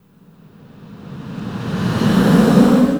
SIGHS 2REV-L.wav